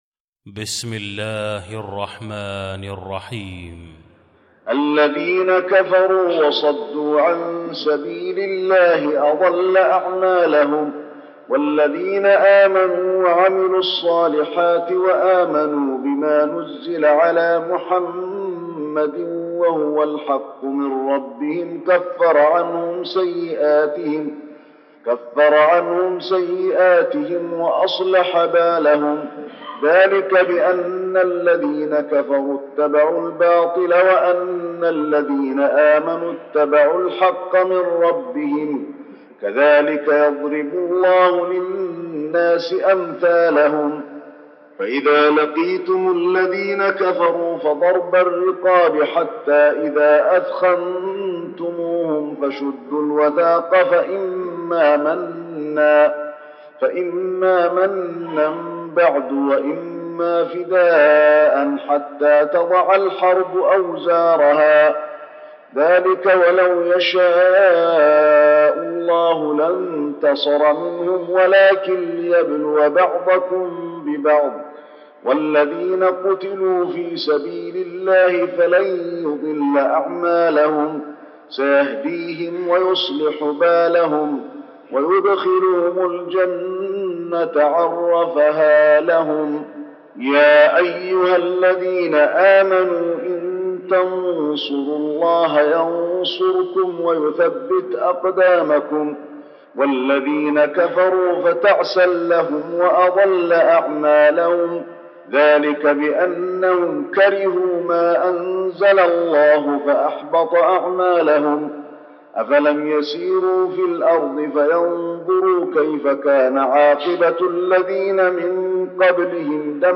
المكان: المسجد النبوي محمد